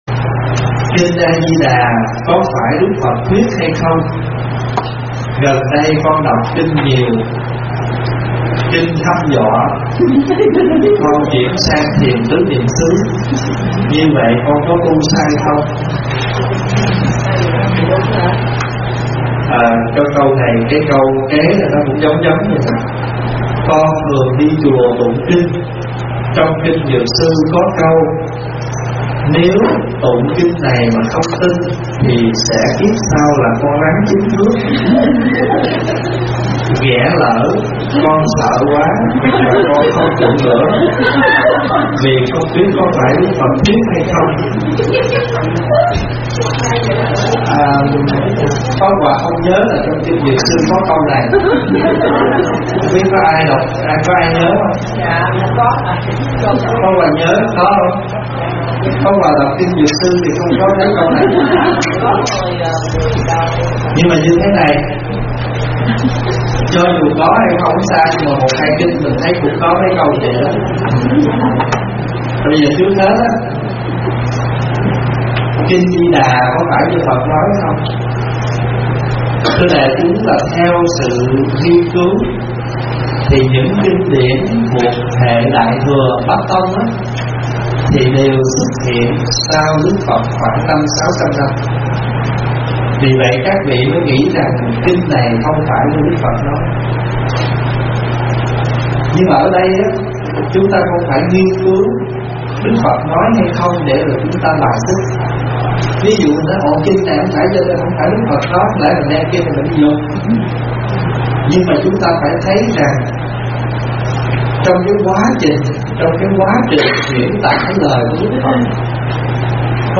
Nghe Mp3 thuyết pháp Tụng Kinh nào mới Đúng? - ĐĐ. Thích Pháp Hòa
Mời quý phật tử nghe mp3 vấn đáp Tụng Kinh nào mới Đúng? - ĐĐ. Thích Pháp Hòa giảng